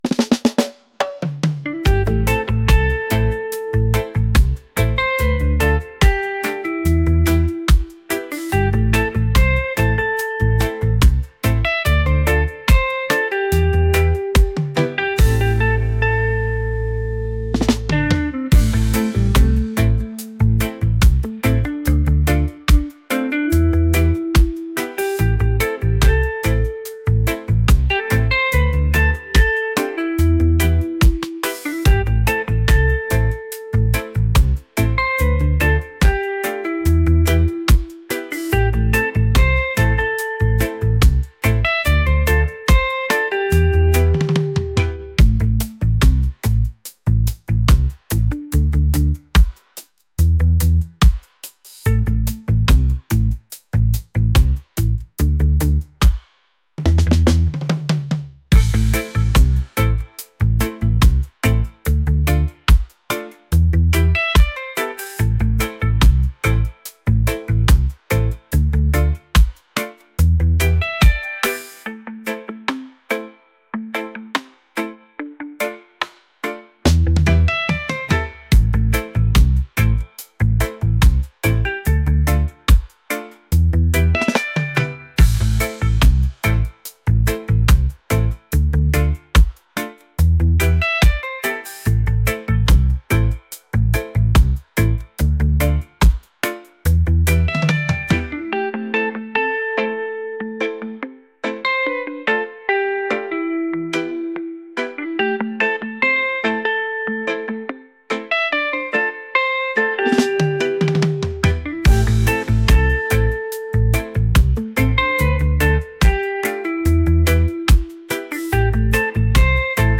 upbeat | reggae